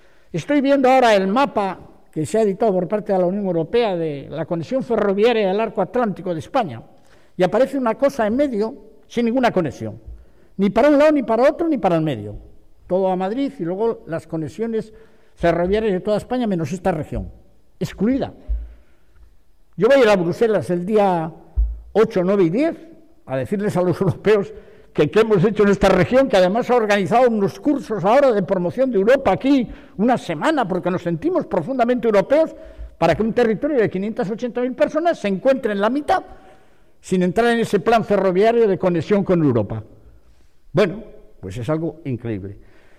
“Hay cosas que tendrían que cambiar para que nos sintiéramos todos partícipes de un país sin privilegios para unos y castigos para otros”, señala el presidente cántabro en la apertura del Diálogo para el Futuro del Trabajo.